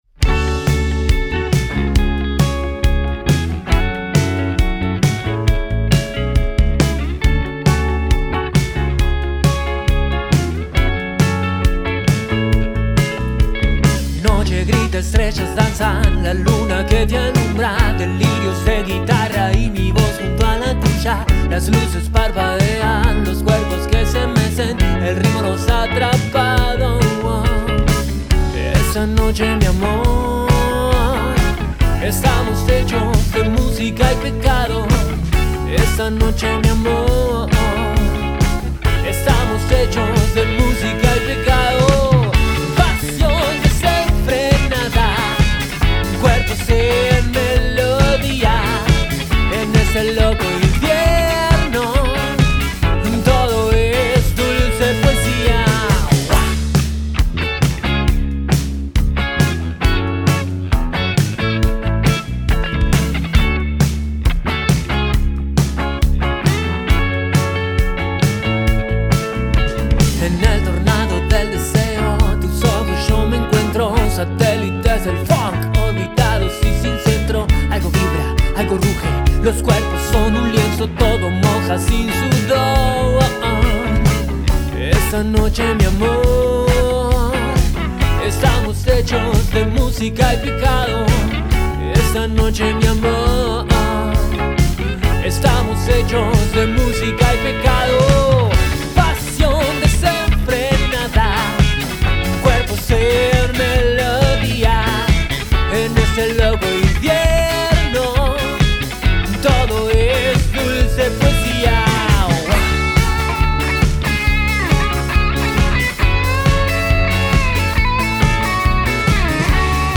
Grabada en vivo el 1 de junio de 2025